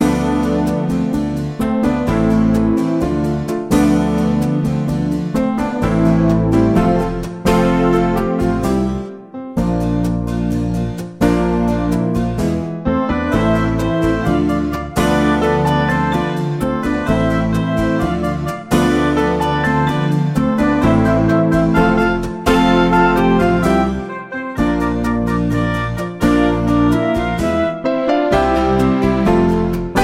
Ein Musical für Kids & Teens
Musicals